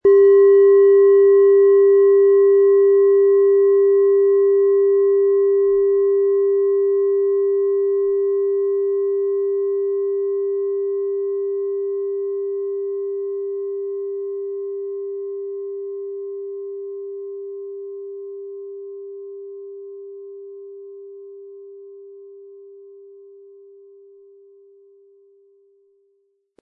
Japanische Klangschale Solfeggio 396 Hz - Befreiung
Die japanische Klangschale mit 396 Hertz entfaltet einen klaren Ton, der innere Ruhe schenkt und Stabilität fördert.
Der Nachhall klingt rein und öffnet einen Raum für Achtsamkeit, Meditation und Klangarbeit.
MaterialBronze